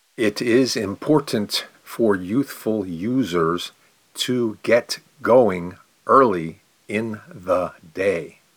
Consonants-pronounce-2_slow.mp3